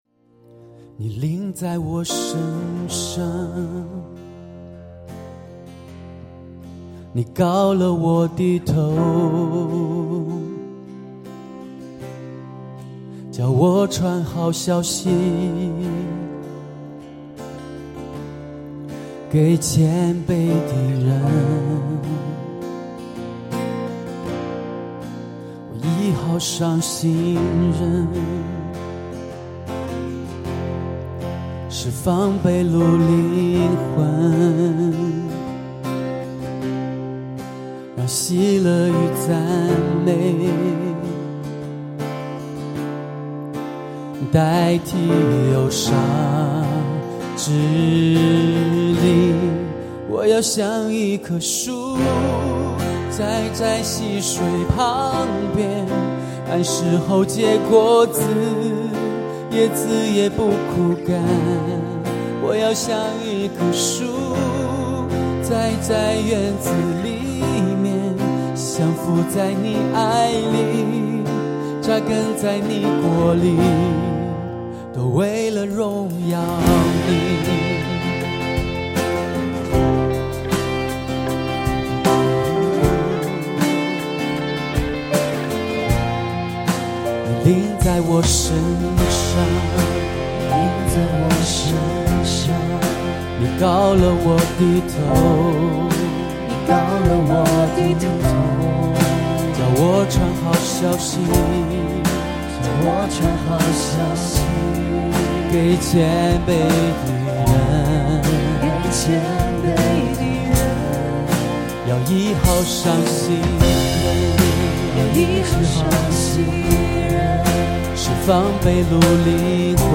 下面给大家听一个和小伙伴们直播录制的版本，目前还没有制作，所以比较粗糙，但是感动是满满的。